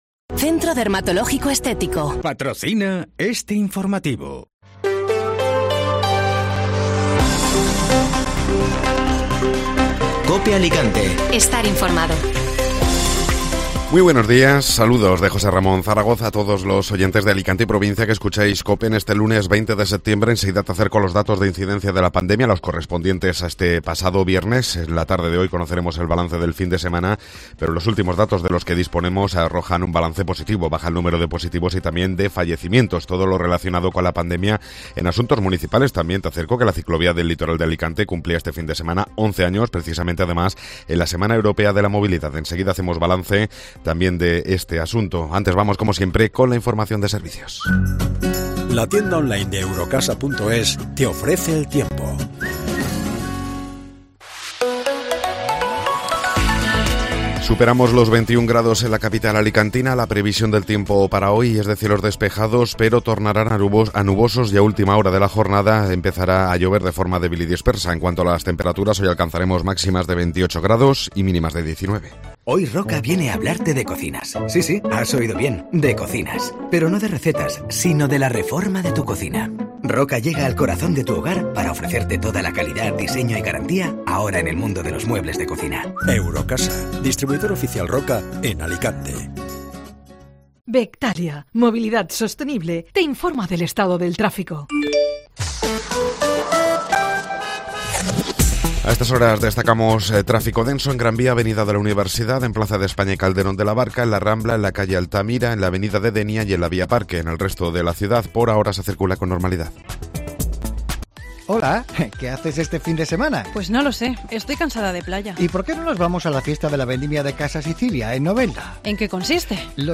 Informativo Matinal (Lunes 20 de Septiembre)